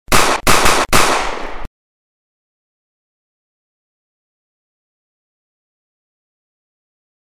Four Shots